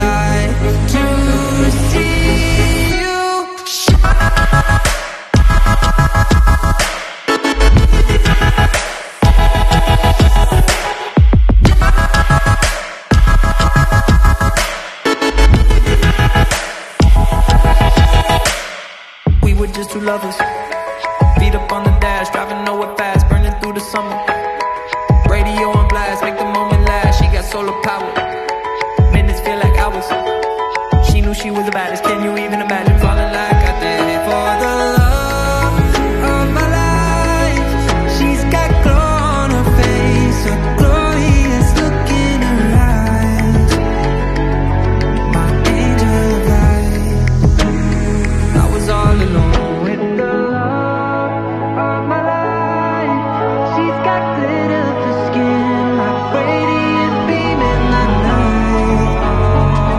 250HP NA KSWAP HONDA JAZZ sound effects free download